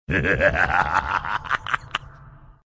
World of Warcraft Undead Laugh-sound-HIingtone
world-of-warcraft-undead-laugh_27440.mp3